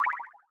Lucki Perc (1).wav